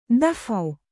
音标: /dafʕ/